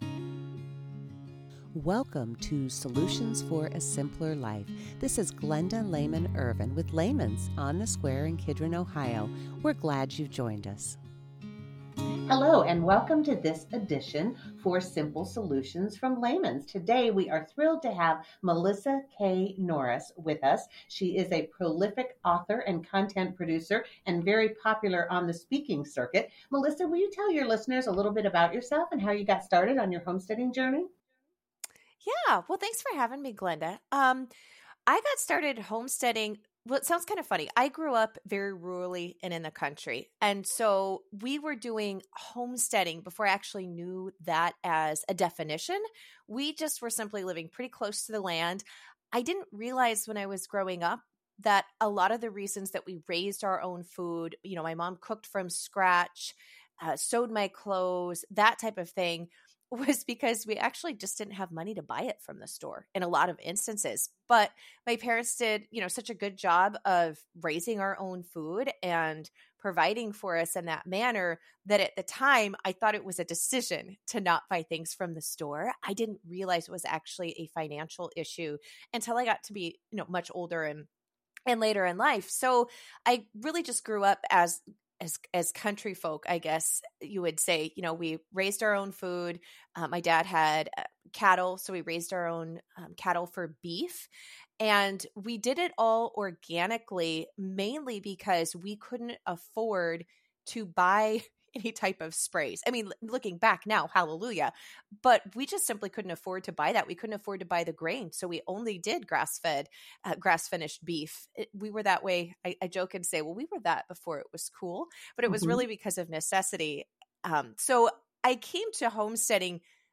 Note to our listeners: This is one of our first in-house recorded podcasts. Please stick with us as we work on improving the sound quality.